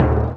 00082_Sound_Drum.mp3